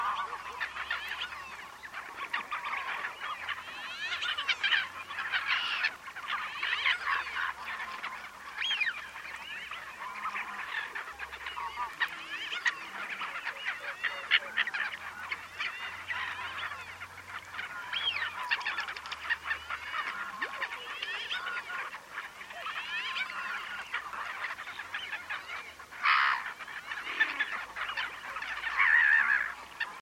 Fuligule milouin - Mes zoazos
fuligule-milouin.mp3